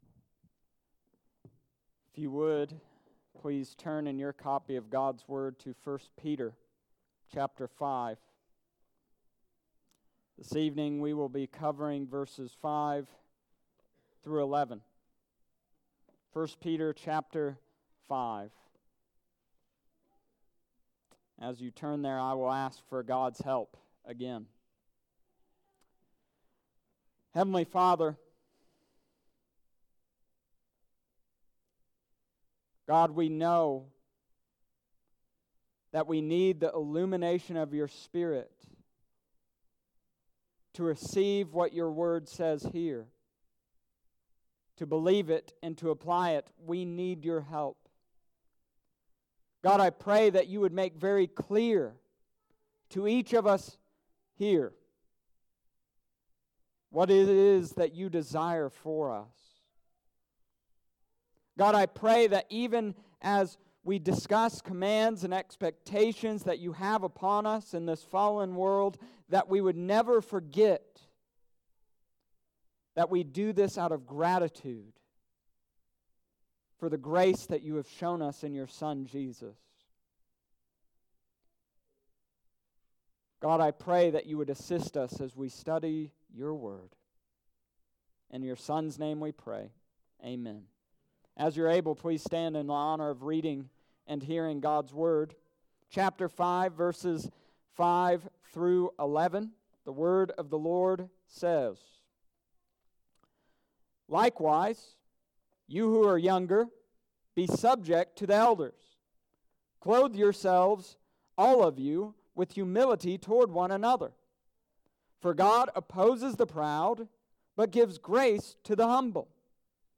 1 Peter Chapter 5 verses 5-11 A charge to All Christians Oct.13th,2019 Sunday evening service